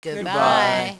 Stevie and the band will welcome you and notify you of new mail.
fwmac_goodbye.wav